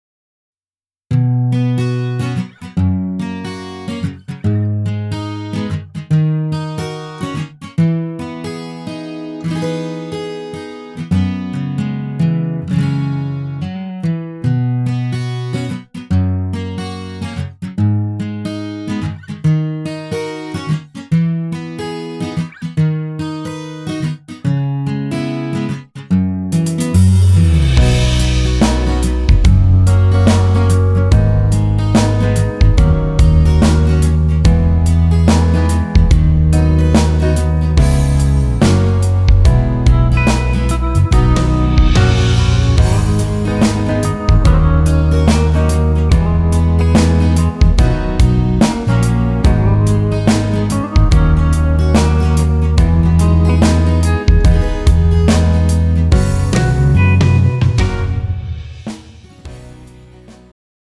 음정 -1키
장르 축가 구분 Pro MR